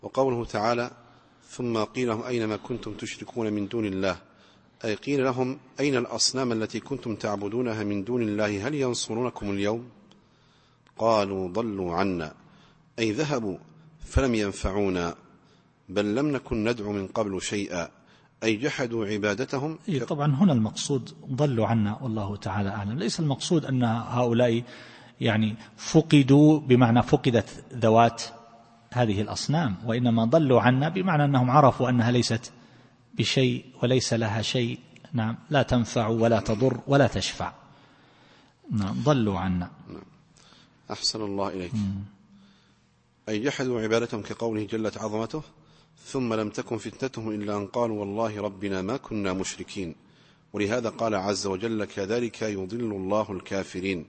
التفسير الصوتي [غافر / 73]